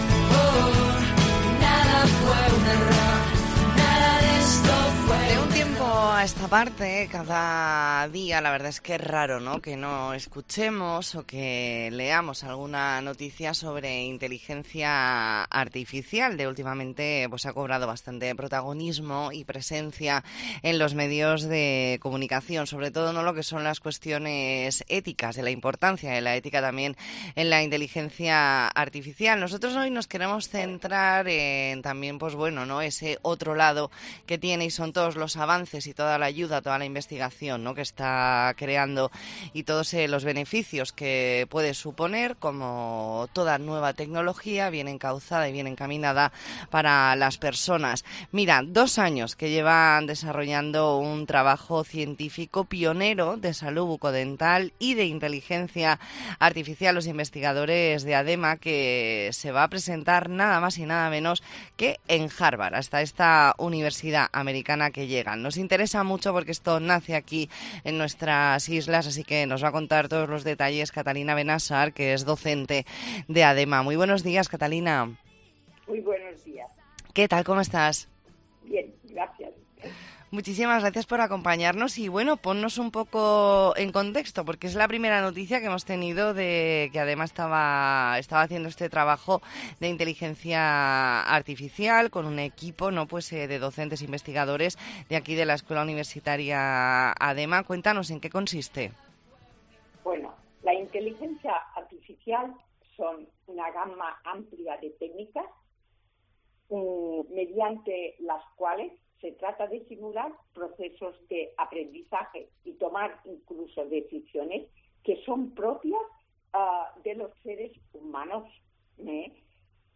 Entrevista en La Mañana en COPE Más Mallorca, martes 24 de octubre de 2023.